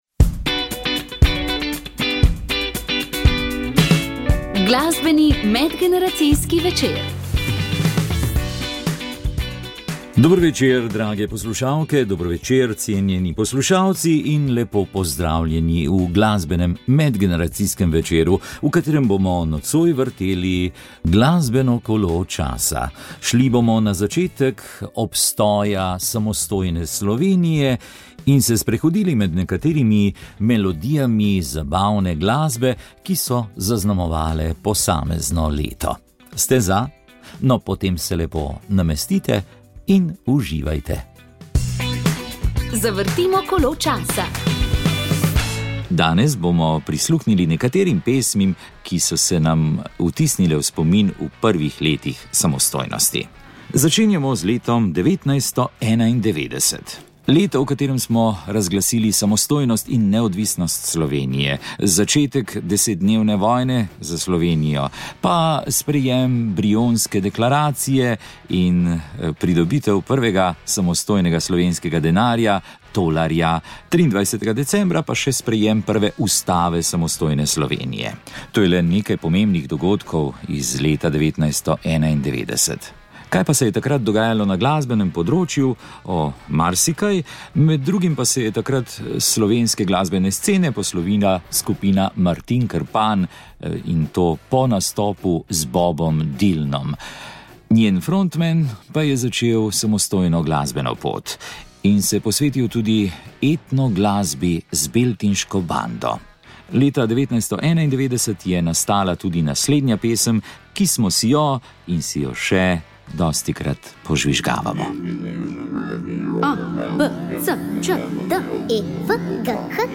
Pogovarjali smo se o njuni poti, odločitvah in o primerjavi opernega sveta v Sloveniji in tujini.